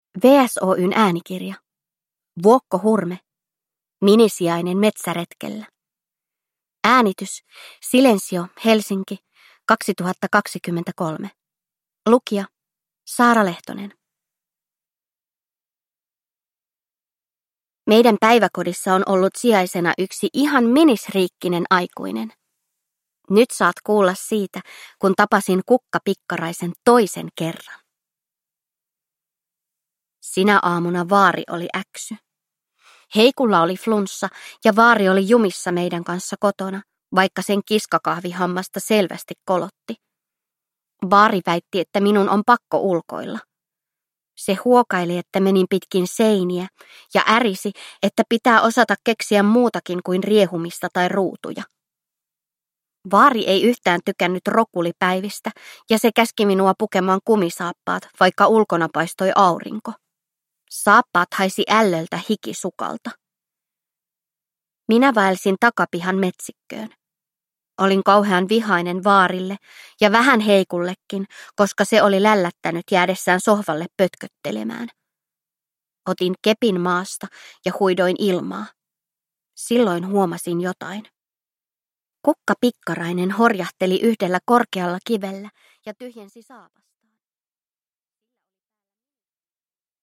Minisijainen metsäretkellä – Ljudbok – Laddas ner